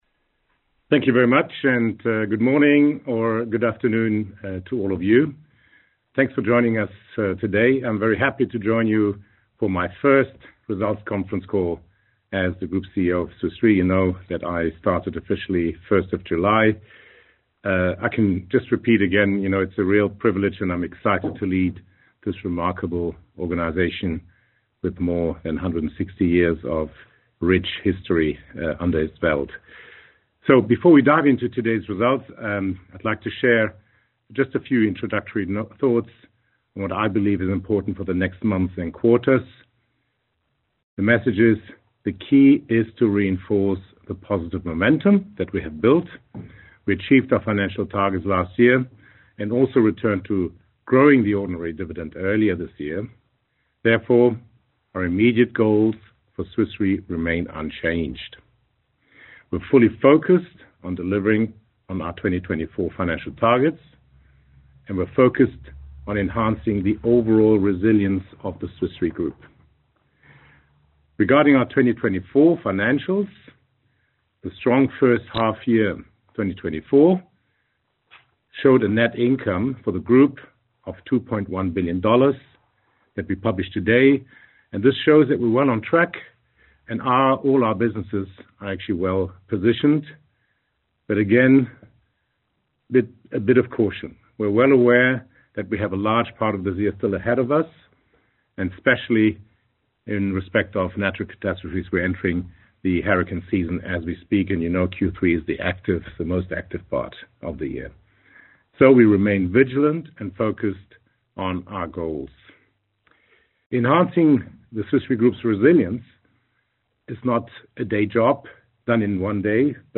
hy-2024-call-recording.mp3